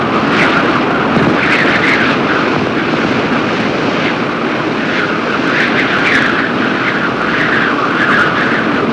Download Whipping sound effect for video, games and apps.
Whipping Sound Effect
whipping.mp3